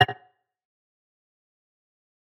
Sounds / UI / UImsg.ogg
UImsg.ogg